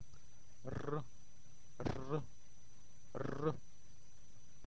Ð_ð - Letter like English r, like in English words rose, raise. It's a ringing R.